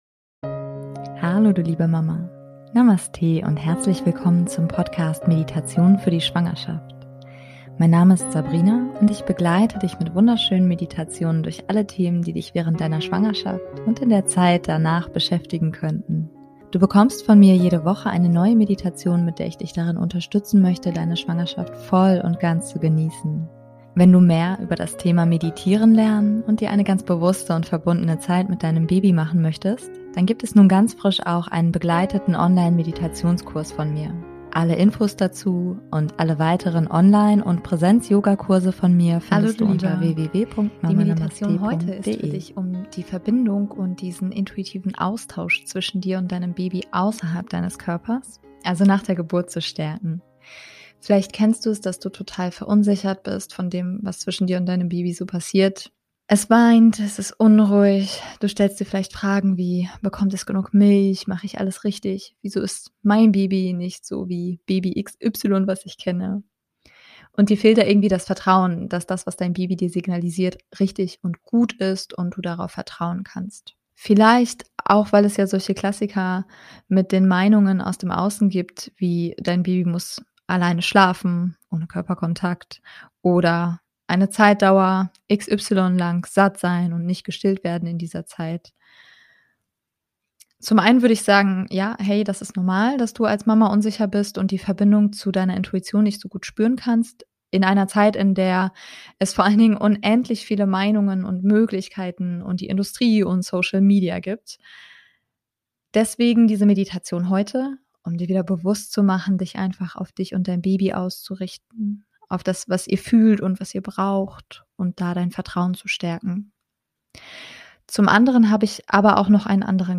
#110 - Vertraue deinem Baby - Meditation [Für Mamas] ~ Meditationen für die Schwangerschaft und Geburt - mama.namaste Podcast